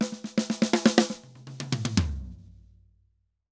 Drum_Break 120_1.wav